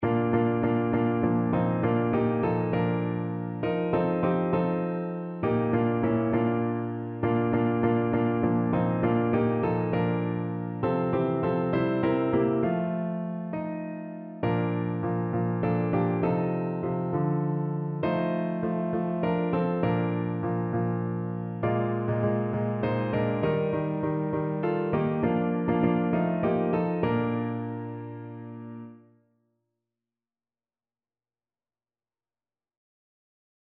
No parts available for this pieces as it is for solo piano.
6/8 (View more 6/8 Music)
Piano  (View more Easy Piano Music)
Classical (View more Classical Piano Music)